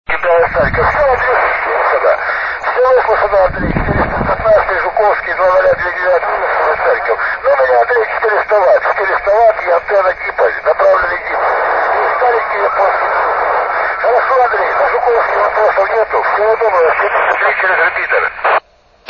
диэкс из украины